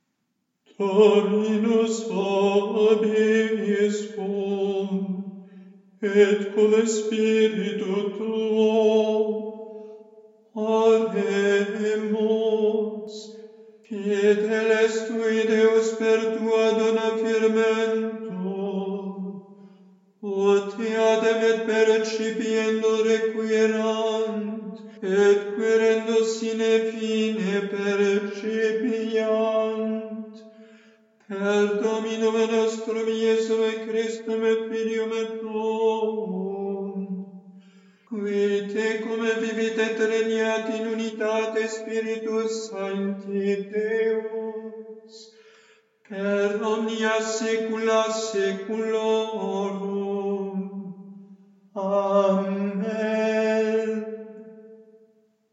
Postcommunion